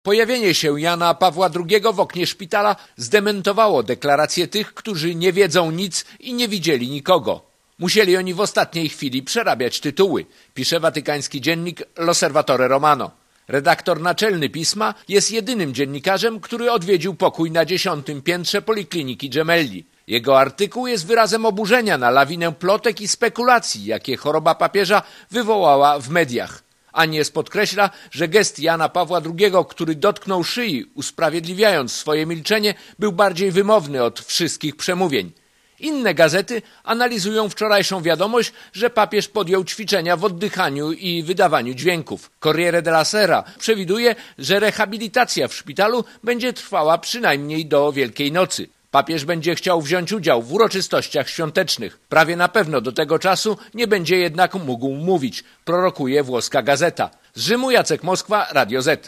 Korespondencja z Rzymu